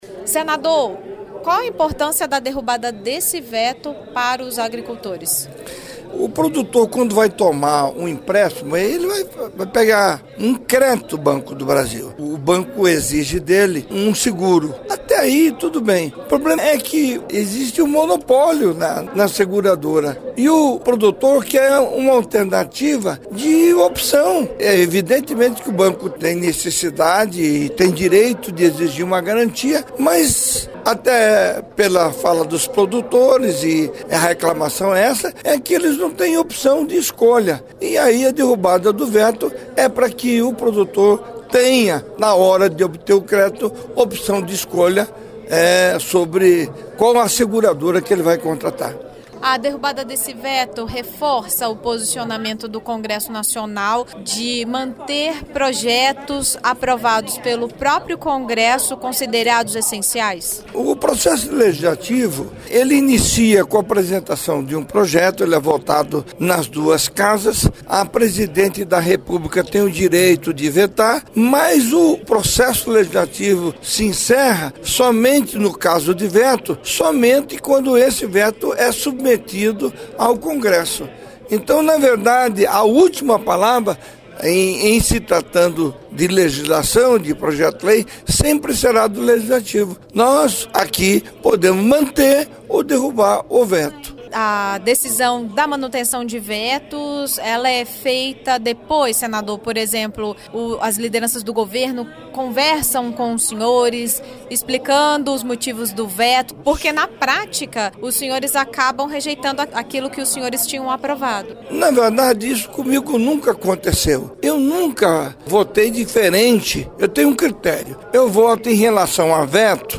Senado em Revista: Entrevistas - 27/05/2016